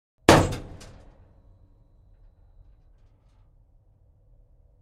На этой странице собраны звуки турели — от механизма поворота до залповой стрельбы.
Единственный выстрел